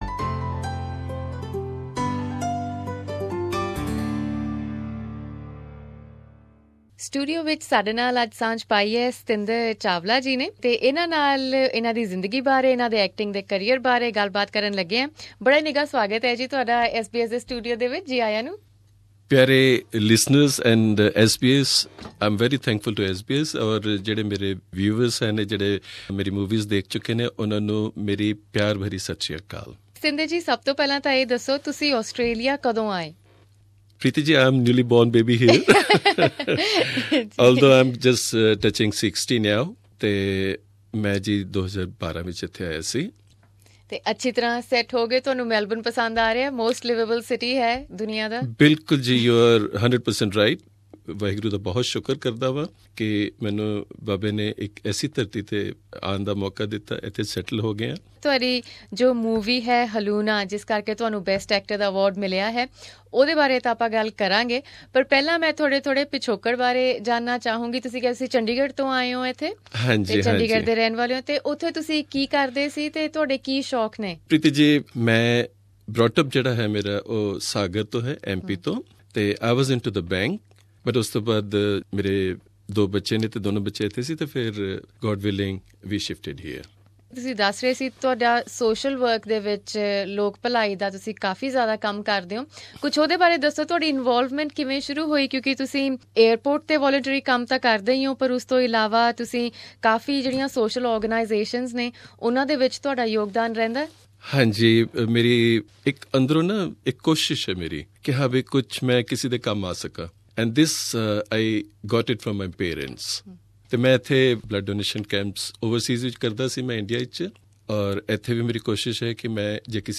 In this interview, he talks to SBS Punjabi about his past career, his interests and film career.